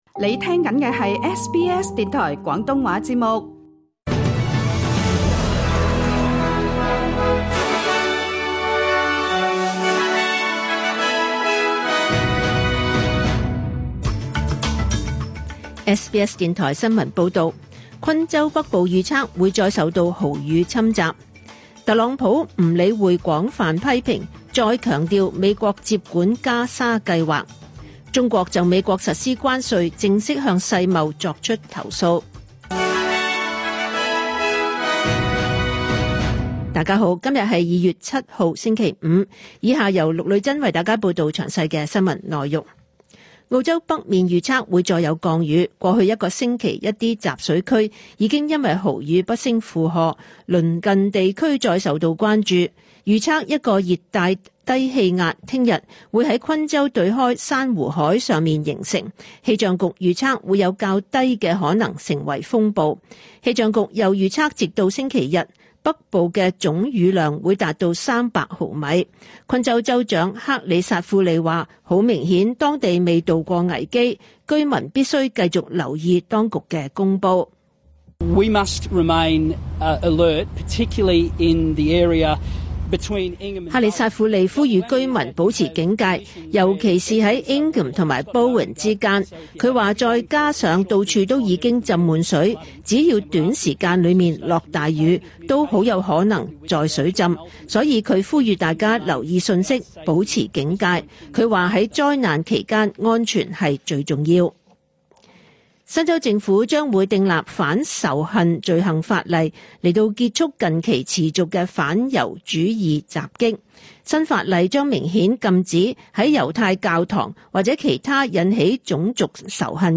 2025 年 2 月 7 日 SBS 廣東話節目詳盡早晨新聞報道。